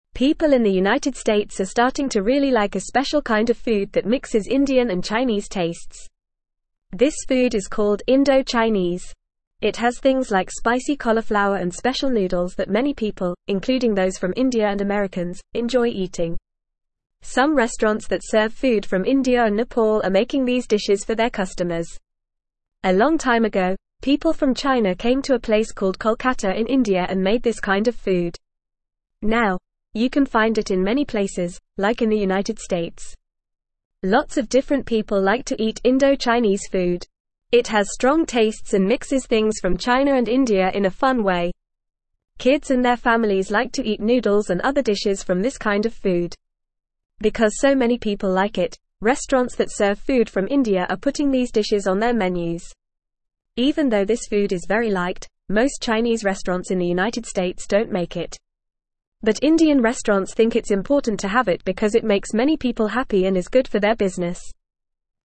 English-Newsroom-Beginner-FAST-Reading-Indo-Chinese-Food-A-Tasty-Mix-of-India-and-China.mp3